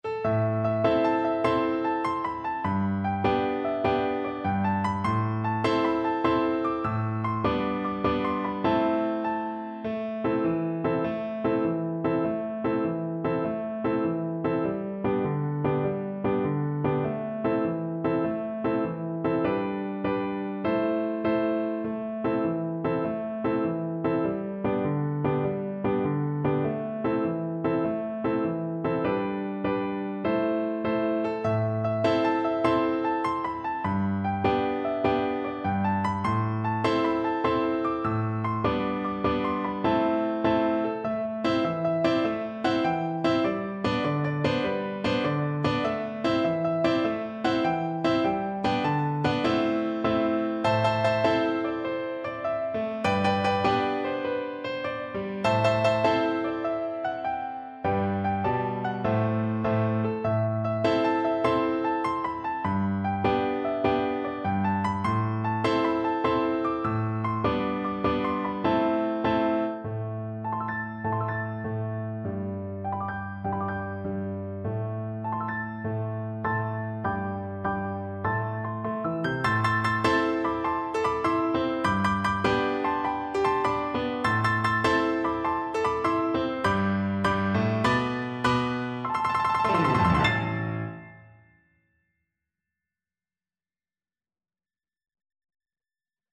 Allegro .=c.100 (View more music marked Allegro)
6/8 (View more 6/8 Music)